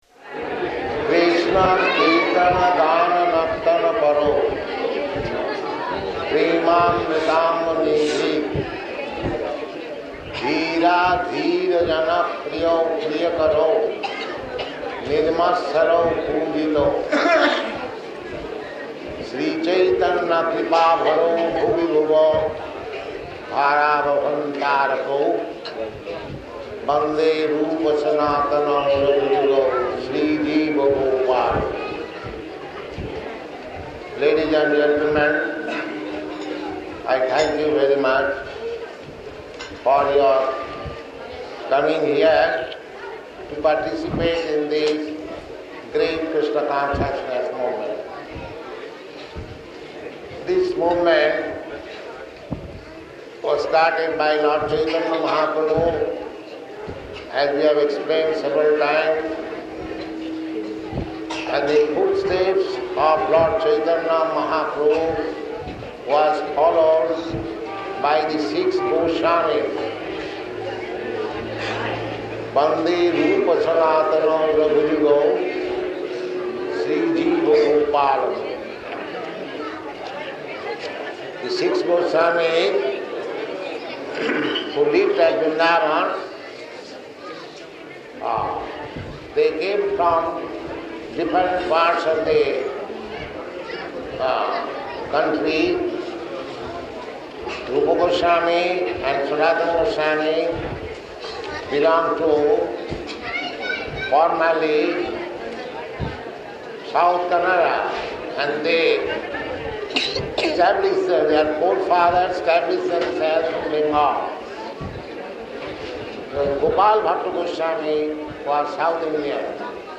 Lecture at Kṛṣṇa Caitanya Maṭha
Type: Lectures and Addresses
Location: Visakhapatnam